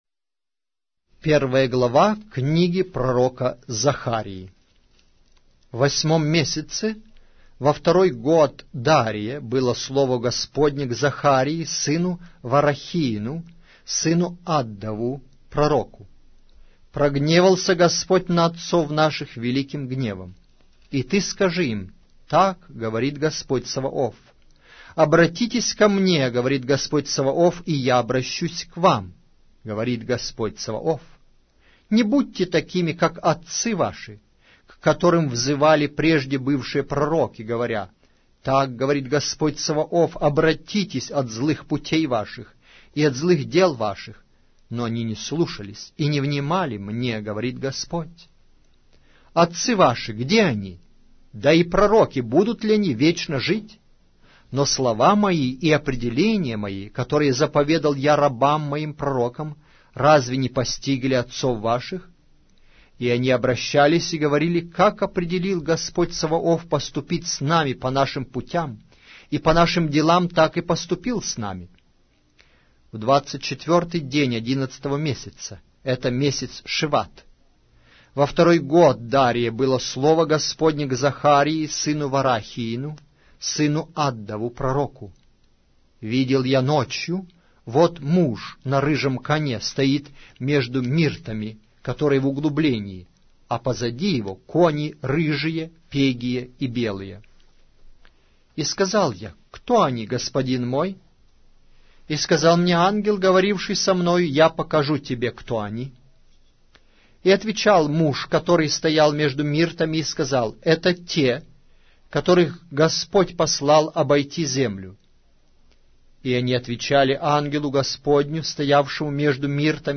Аудиокнига: Пророк Захария